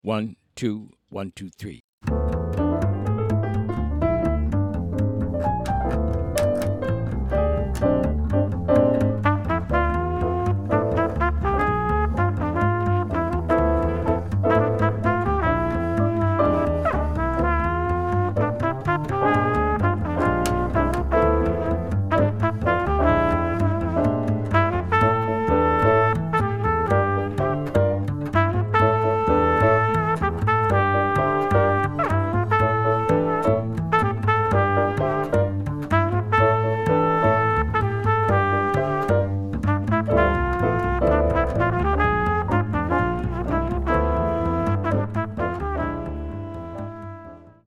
comp (swing/Latin)